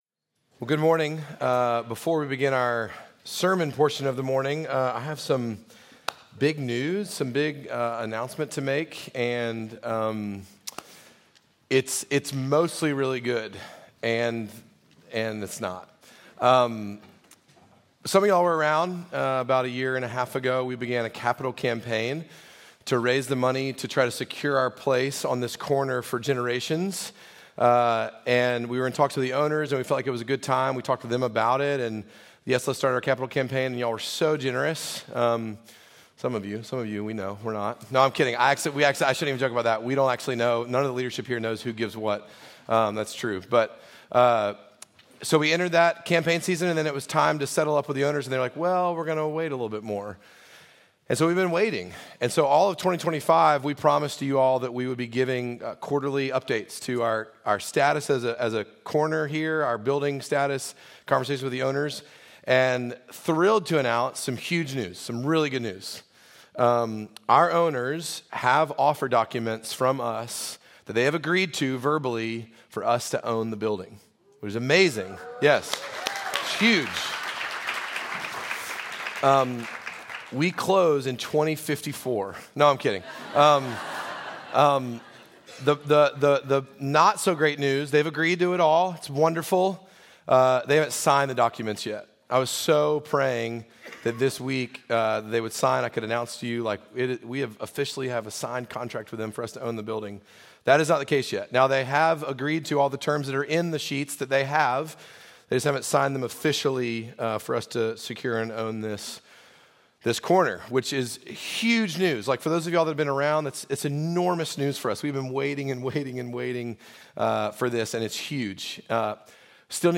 Midtown Fellowship 12 South Sermons